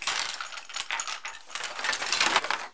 horror
Skeleton Bones Rattle 6